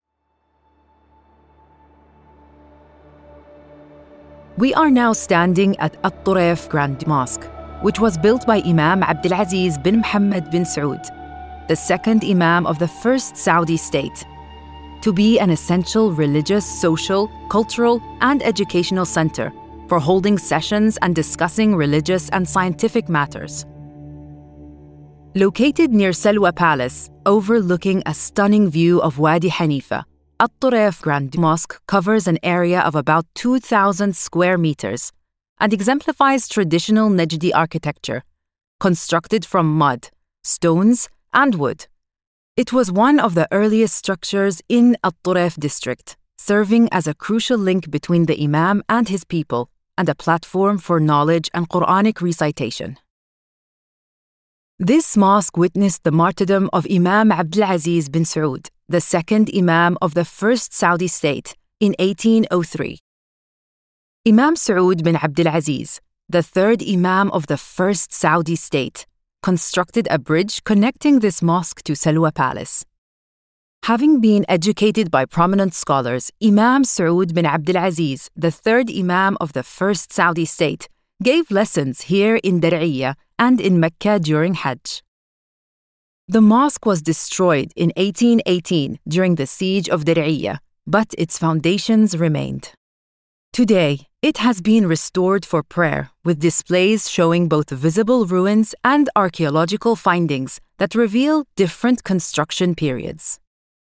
Narração
Tenho um estúdio de gravação profissional em casa e emprestei minha voz para uma ampla gama de projetos de alto nível.
ProfundoBaixo